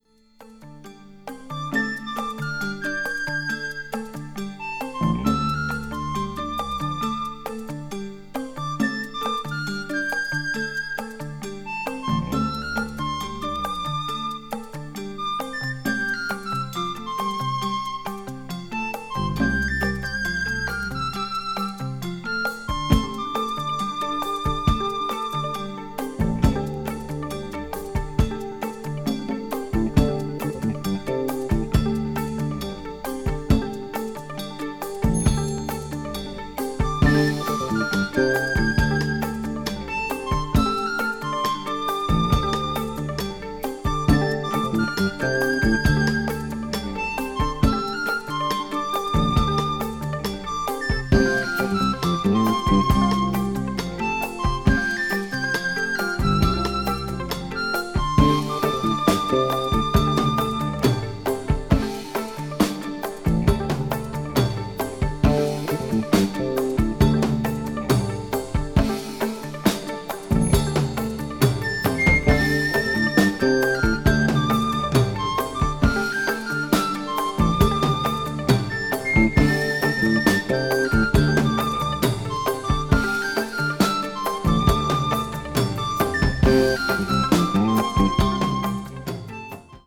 エスニックなテイストがブレンドされたリズミック・チューンA1/B1がオススメです。